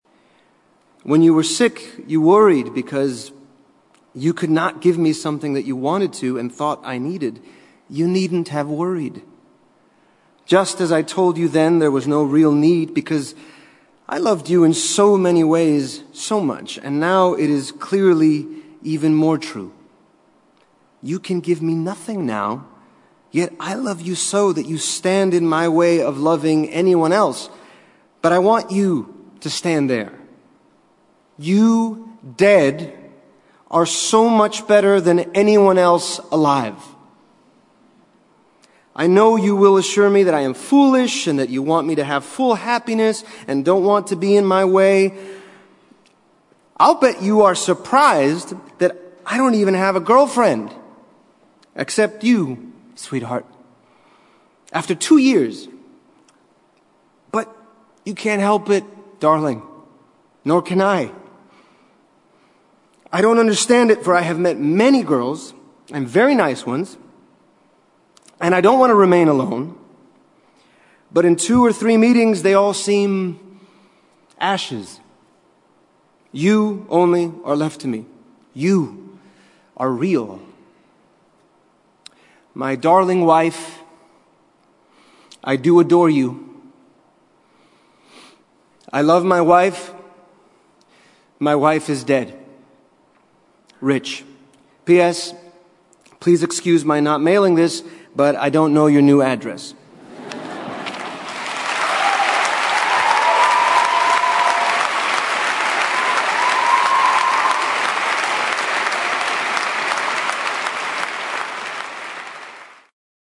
在线英语听力室见信如晤Letters Live 第17期:'奥斯卡伊萨克'读信:我爱我的妻子,她去世了(2)的听力文件下载,《见信如唔 Letters Live》是英国一档书信朗读节目，旨在向向书信艺术致敬，邀请音乐、影视、文艺界的名人，如卷福、抖森等，现场朗读近一个世纪以来令人难忘的书信。